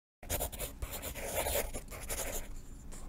art_sketching.ogg